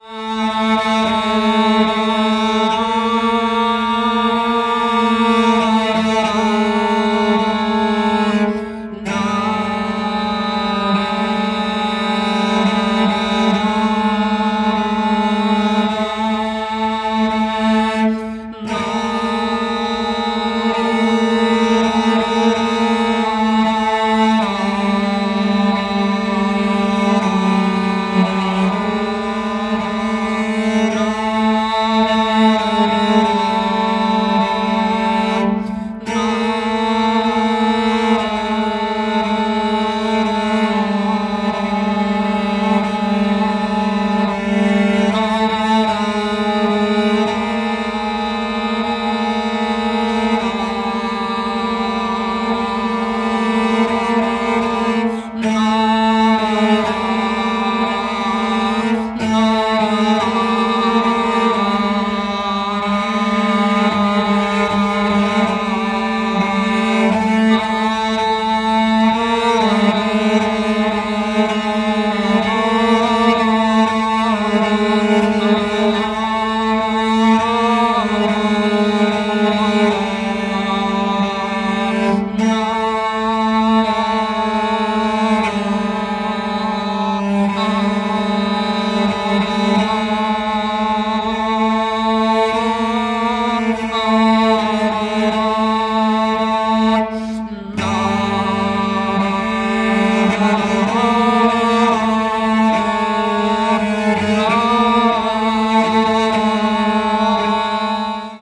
チェロの音色の可能性を独自の研究でコントロール、完全に物音化した抽象反復やら鬼気迫る摩擦音を様々な角度より放射。